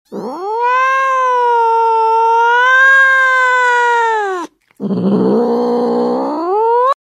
Cat Language (Part 9) 😾 sound effects free download